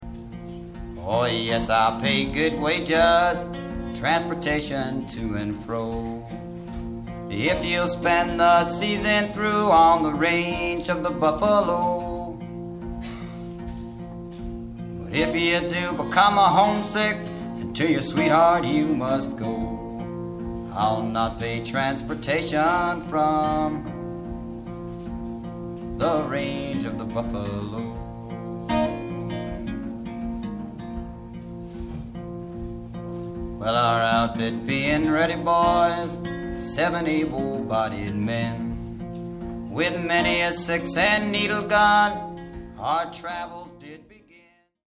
60年代の「コーヒーハウス」文化を匂わす温もりのある録音が聴き所。
ハーモニカ